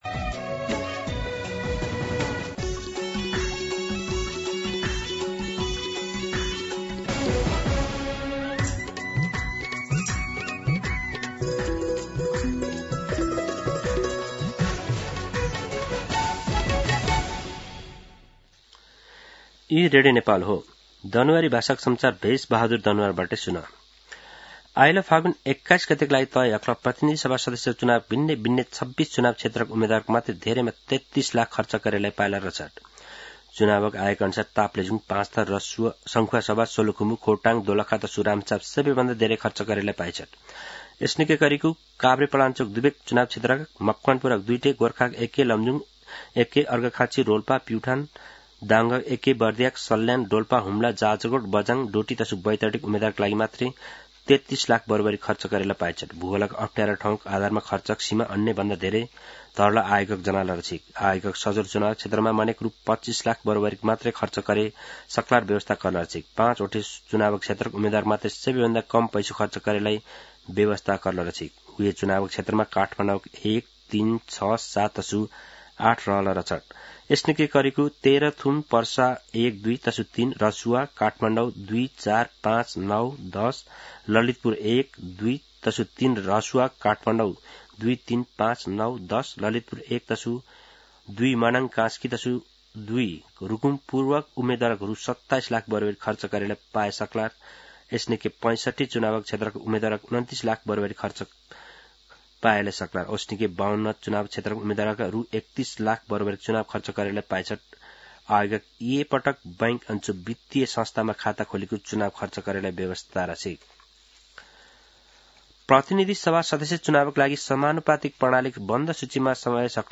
दनुवार भाषामा समाचार : १९ पुष , २०८२